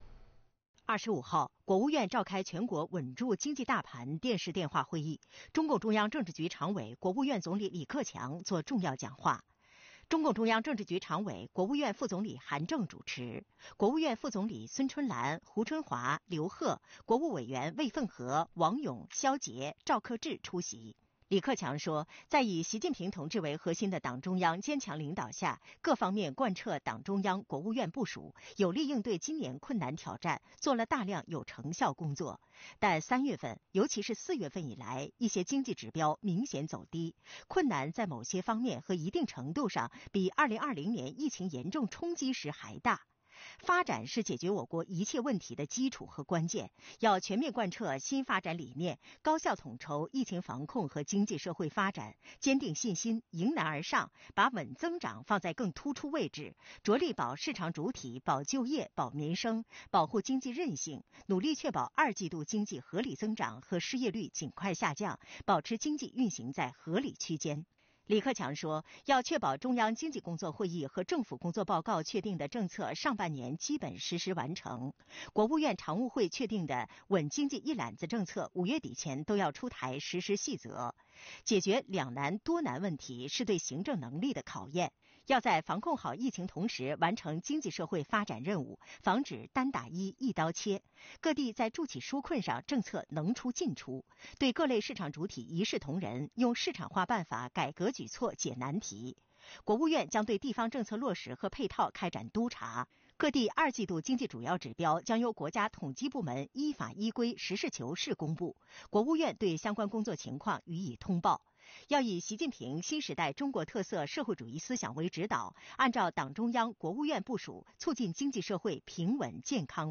韩正主持
5月25日，国务院召开全国稳住经济大盘电视电话会议。中共中央政治局常委、国务院总理李克强作重要讲话。